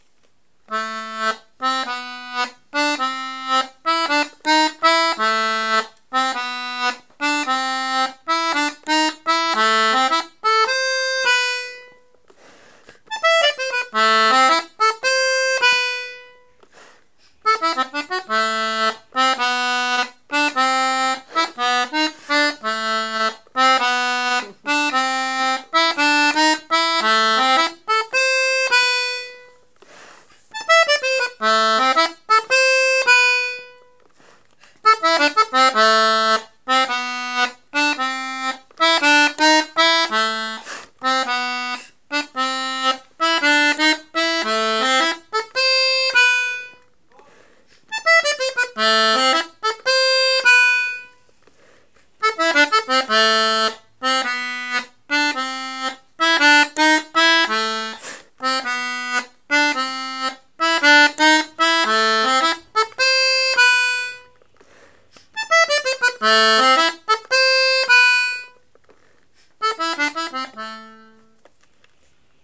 l'atelier d'accordéon diatonique
L'accompagnement n°2 comporte un contre chant et une rythmique à la main gauche
contrechant